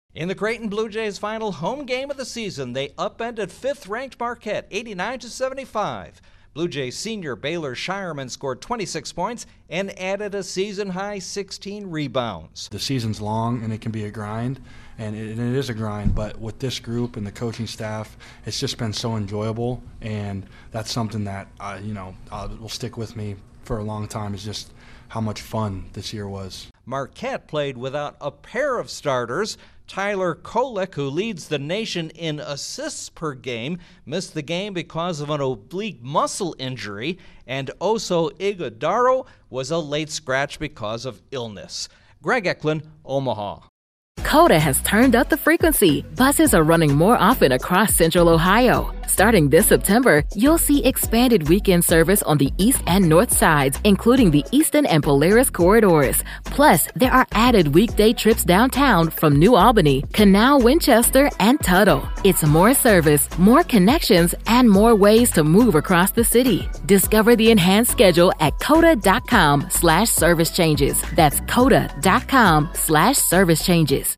Creighton takes advantage of a short-handed Marquette. Correspondent